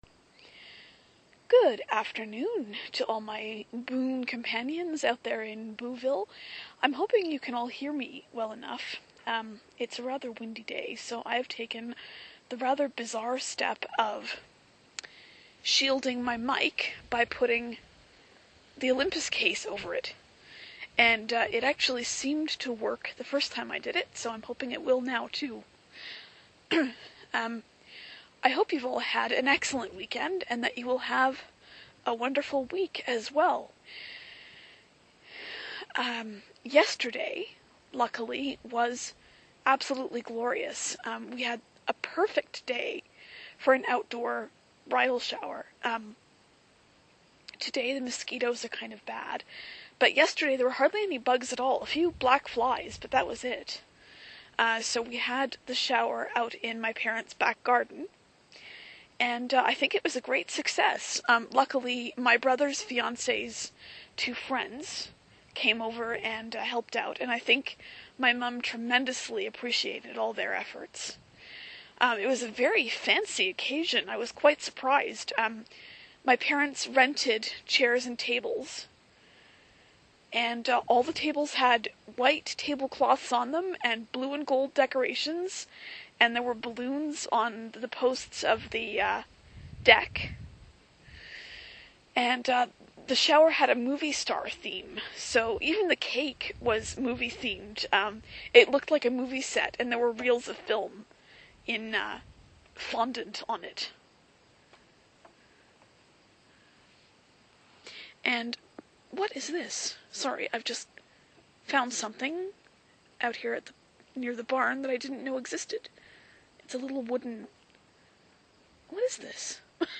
Another Boo from the farm, in which I waffle a lot as usual. =)